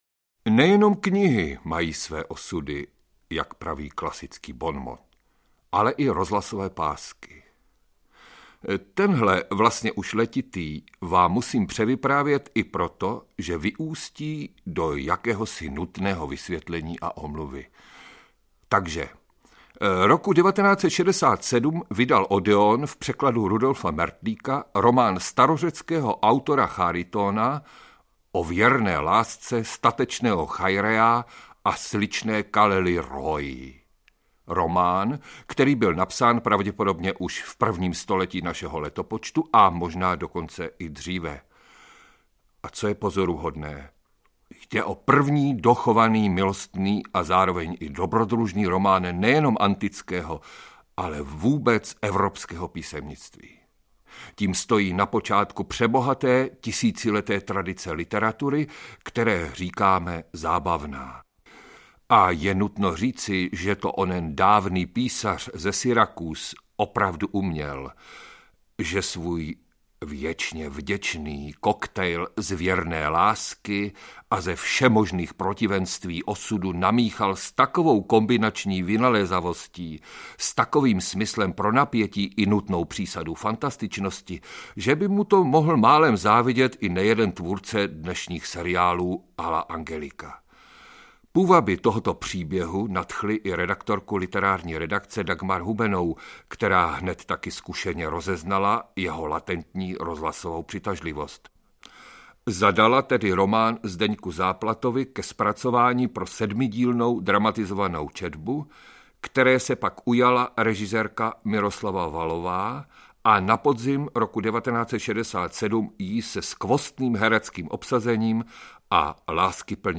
Dramatizovaná četba z roku 1968
o-verne-lasce-statecneho-chairea-a-slicne-kallirhoy-audiokniha